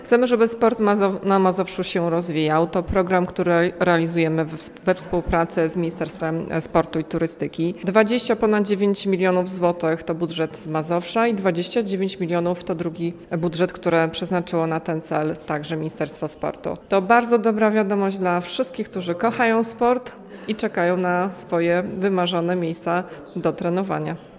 O celach programu mówi członkini zarządu województwa mazowieckiego Anna Brzezińska.